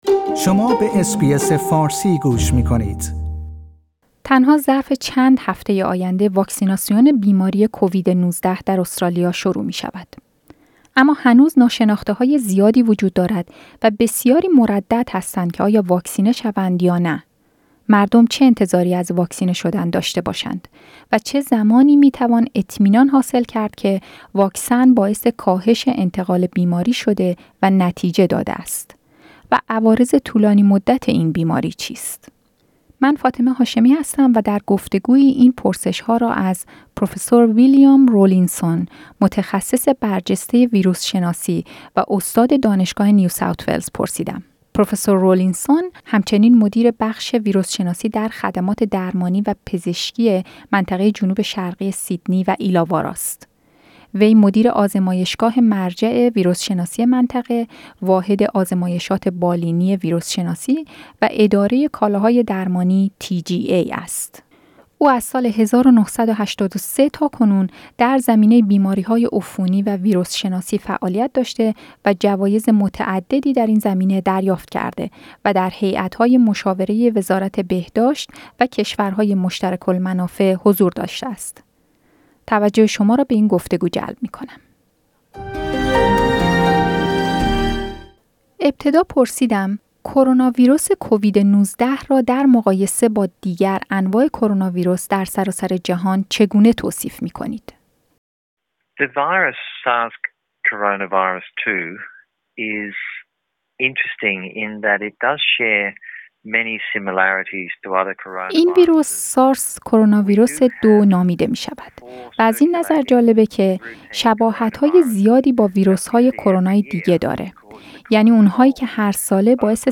و عوارض طولانی مدت این بیماری چیست؟ در گفتگویی